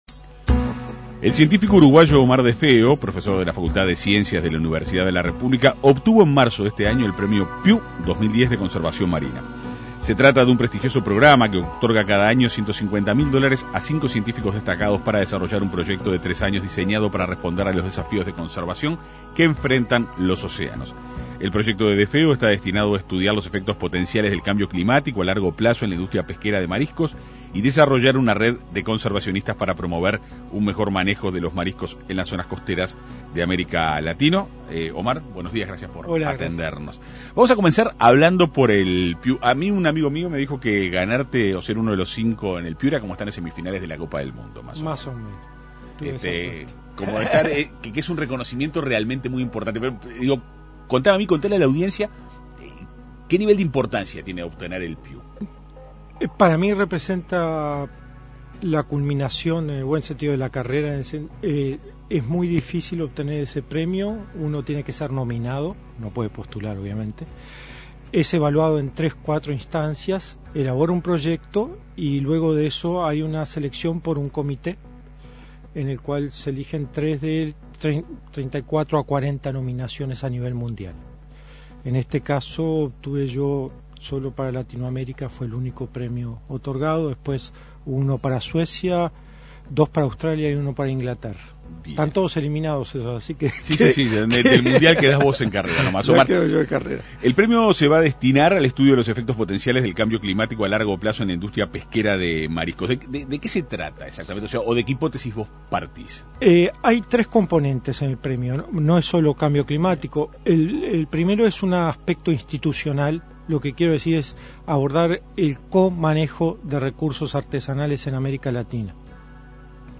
Se trata de un prestigioso programa que otorga cada año 150.000 dólares a cinco científicos destacados, para desarrollar un proyecto de tres años diseñado con el fin de responder a los desafíos de conservación que enfrentan nuestros océanos. El investigador fue entrevistado en la Segunda Mañana de En Perspectiva.